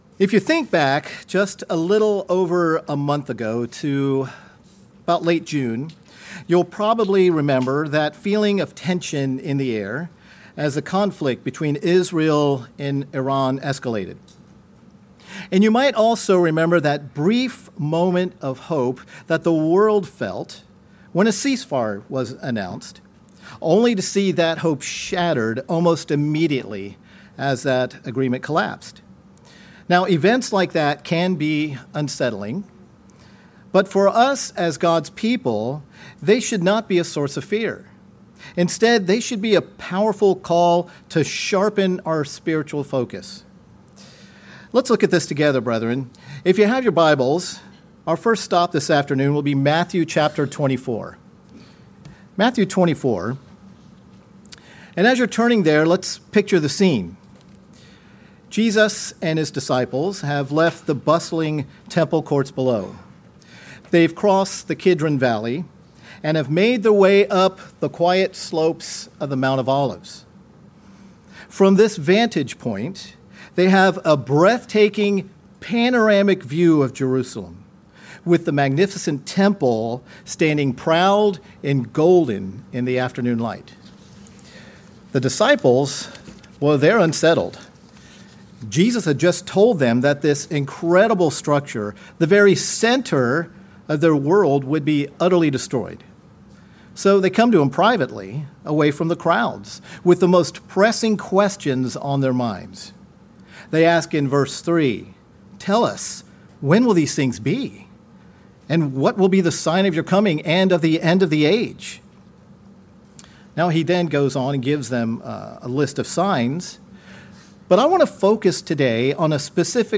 But what if Jesus Christ gave a direct command for moments just like these? This sermonette explores that powerful instruction and reveals a practical, biblical framework for finding stability and peace, no matter how chaotic the world may become.